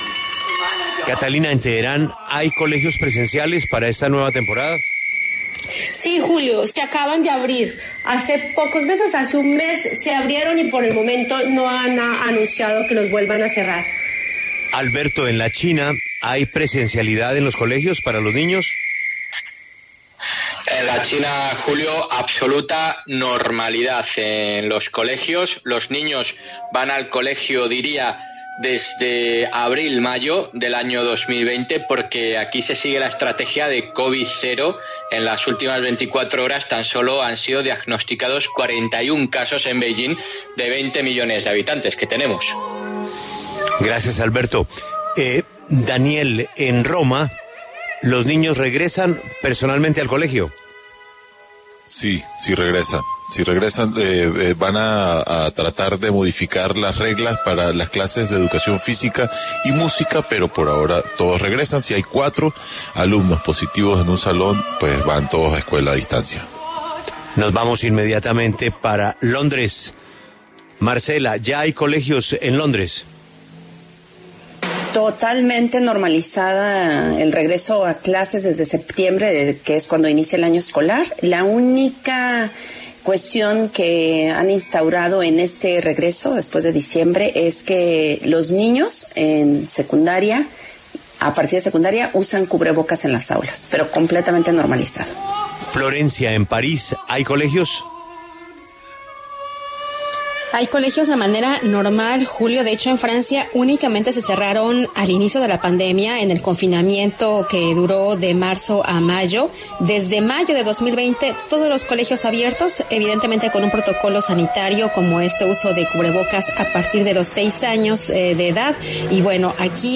En diálogo con La W